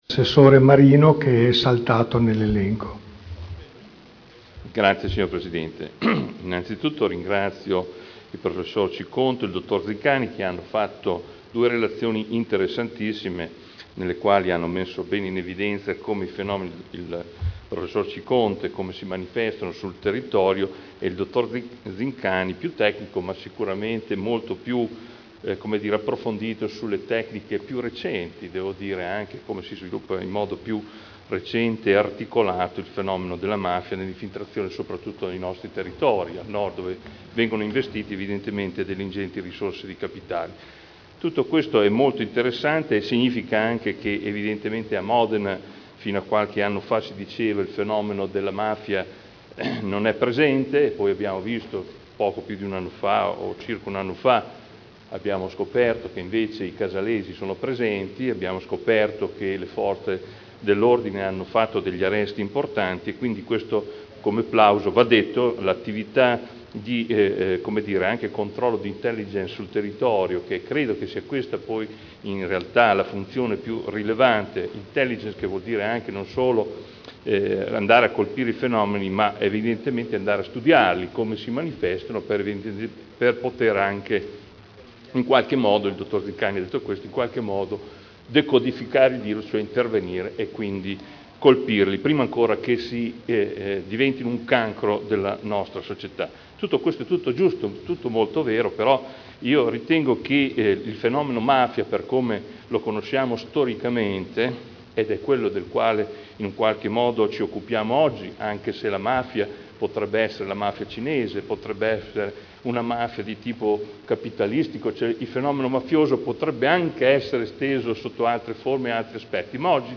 Seduta del 07/10/2010. Intervento del Consigliere Adolfo Morandi al Consiglio Comunale su: Politiche di prevenzione e contrasto alle infiltrazioni mafiose.